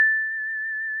beep2.wav